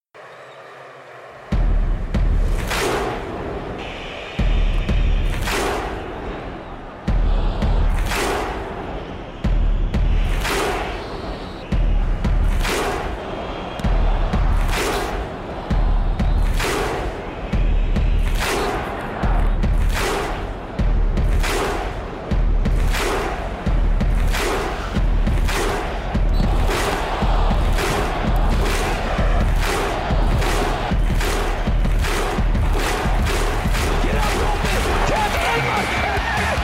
Download “Iceland Viking Thunder Clap 2”
Iceland-Viking-Thunder-Clap-2-1.mp3